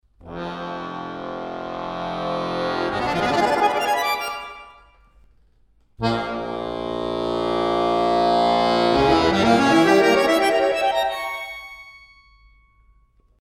Звук акордеона